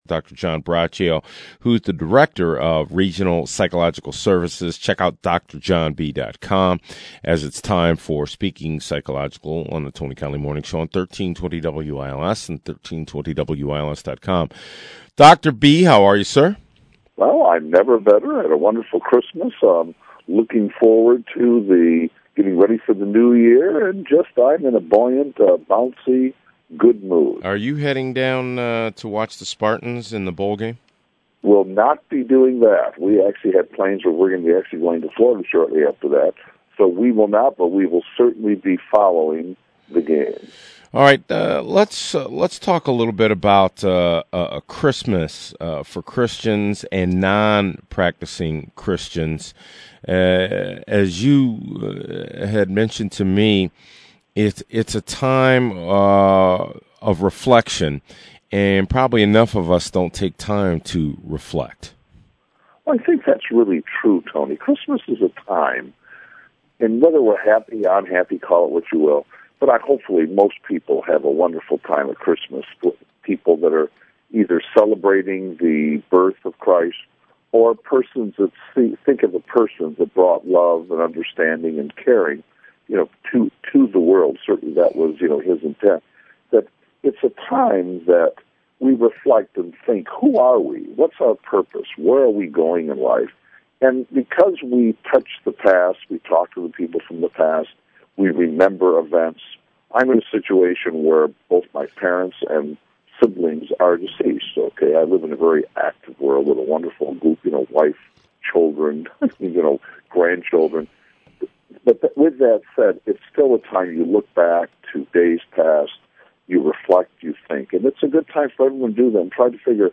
on 1320 a.m. in Lansing